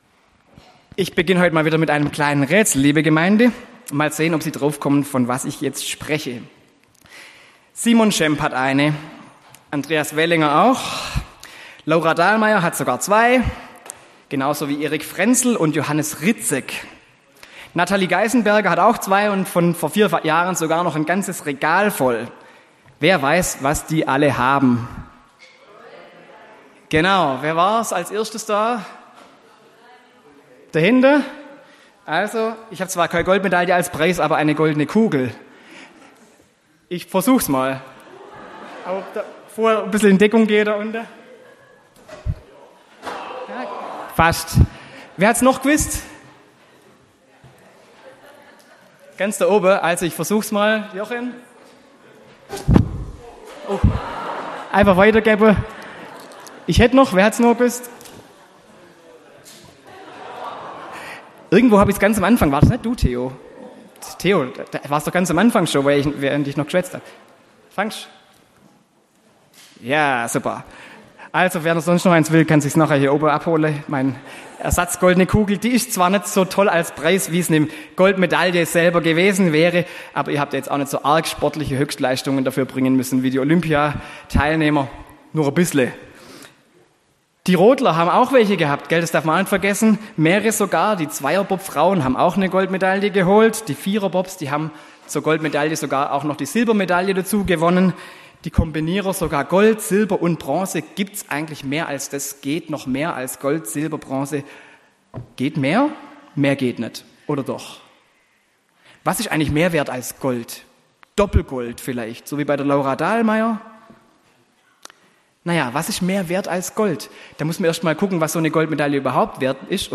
Predigt